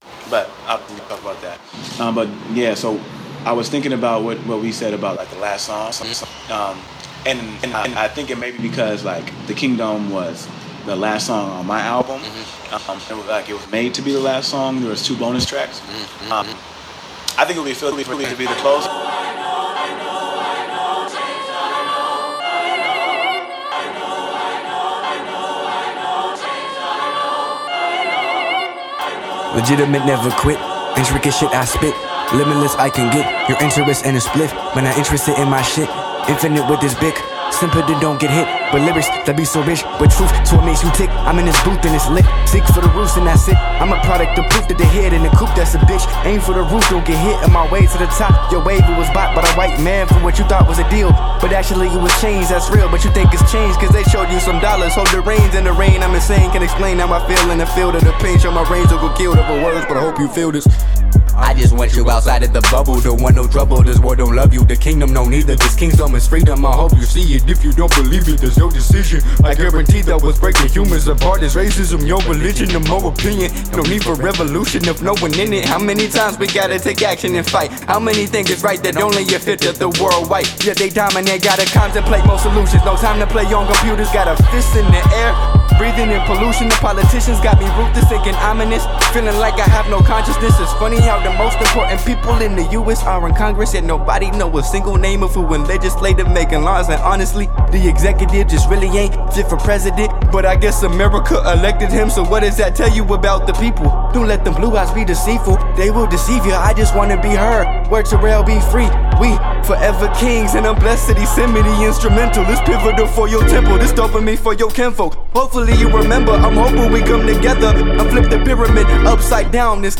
Seattle Hip Hop.
Recorded at AD1 Studios, Seattle, WA